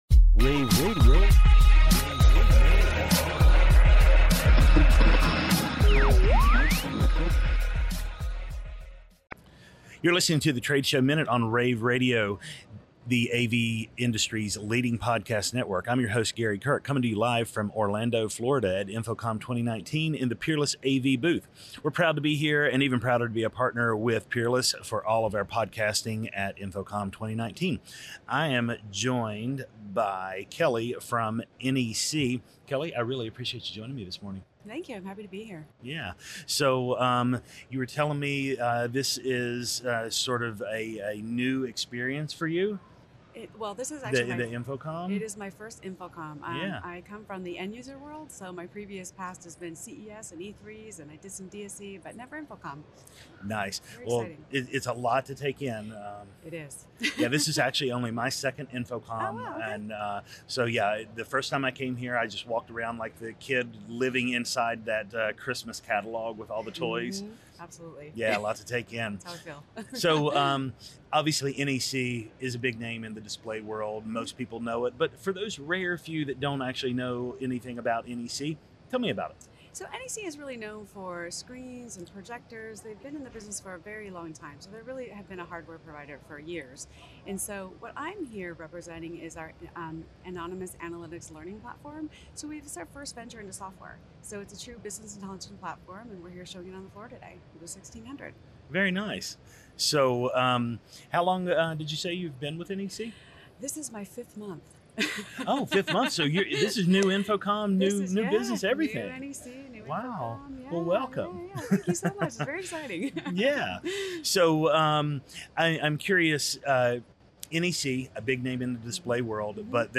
interviews
June 13, 2019 - InfoComm, InfoComm Radio, Radio, rAVe [PUBS], The Trade Show Minute,